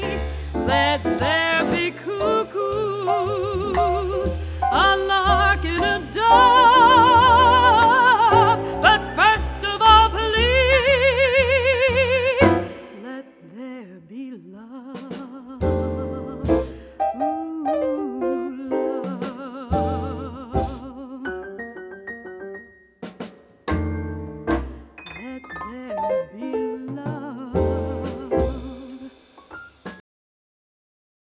Sample These Song Snippets from the Album